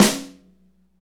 Index of /90_sSampleCDs/Northstar - Drumscapes Roland/SNR_Snares 1/SNR_Motown Snrsx